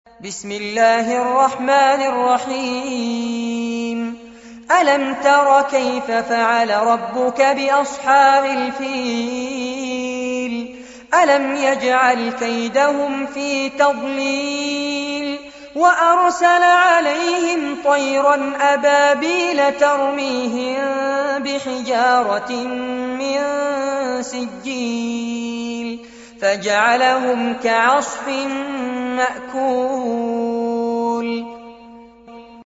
عنوان المادة سورة الفيل- المصحف المرتل كاملاً لفضيلة الشيخ فارس عباد جودة عالية